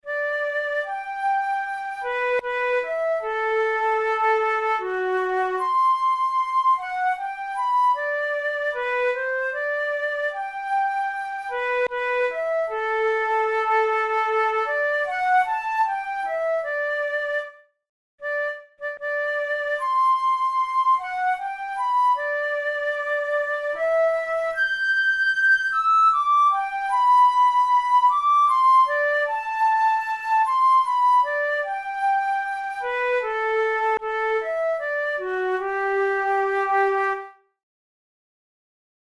InstrumentationFlute solo
KeyG major
RangeF#4–G6
Time signature3/4
Tempo76 BPM
Etudes, Written for Flute